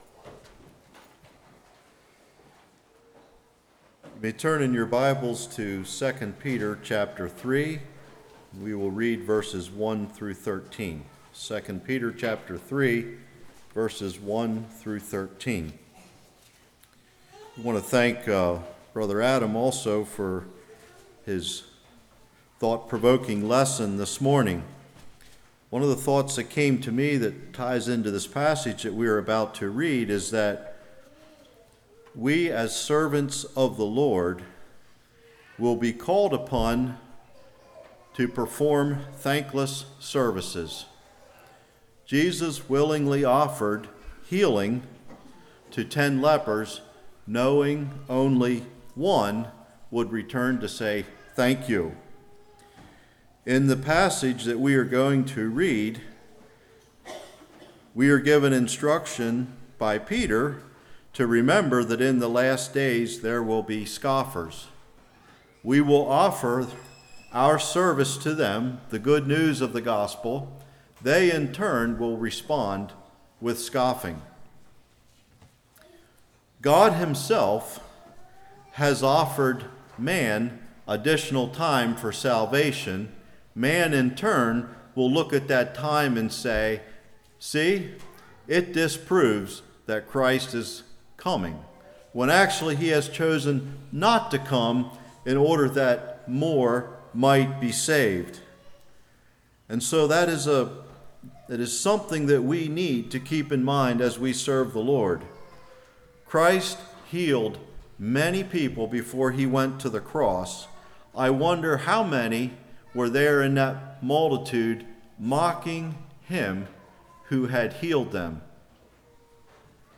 2 Peter 3:1-13 Service Type: Revival The Will be Scoffers God Will Keep His Word The Lord is Not Slack How Shall We Act?